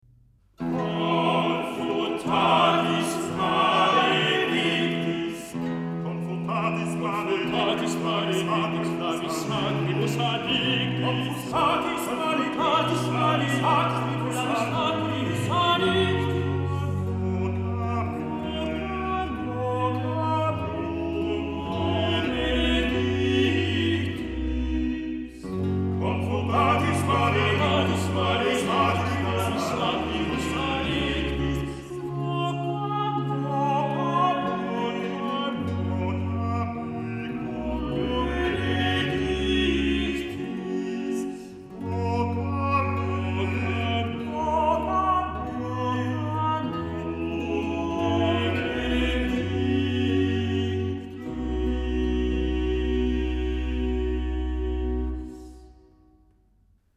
Барочное-лирическое-эпическое.